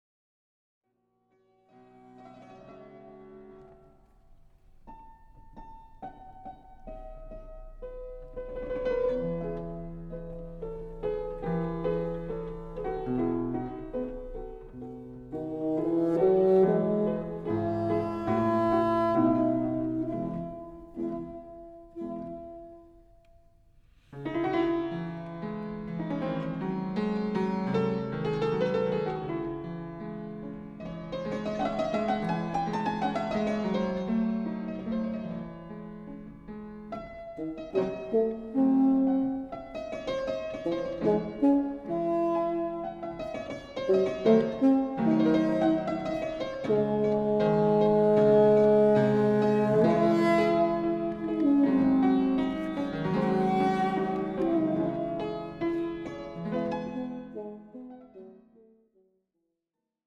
bassoon